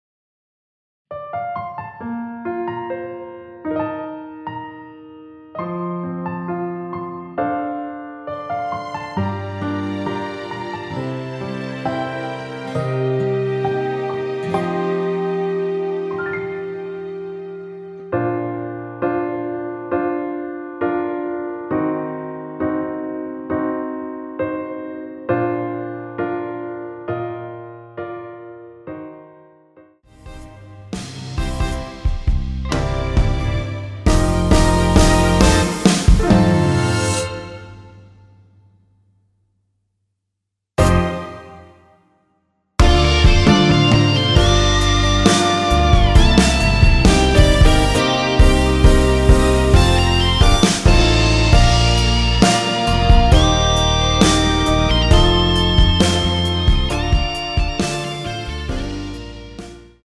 원키에서(+3)올린 MR입니다.
Bb
앞부분30초, 뒷부분30초씩 편집해서 올려 드리고 있습니다.
중간에 음이 끈어지고 다시 나오는 이유는